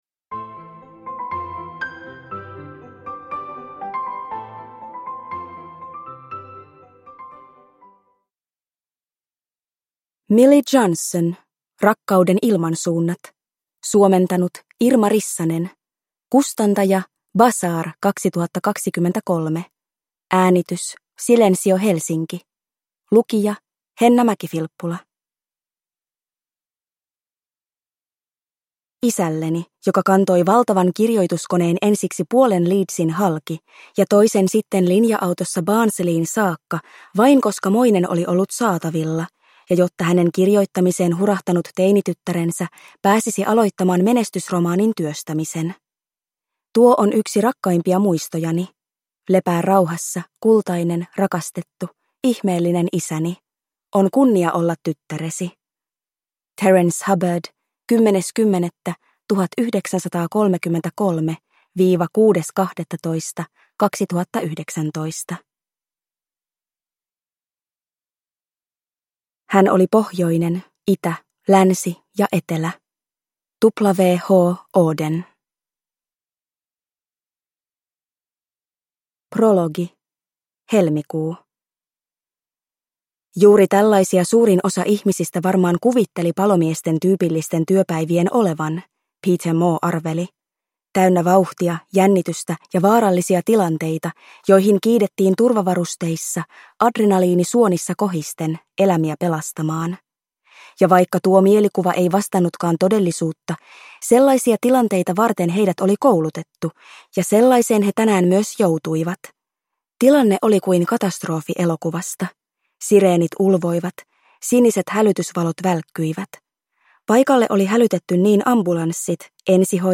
Rakkauden ilmansuunnat – Ljudbok – Laddas ner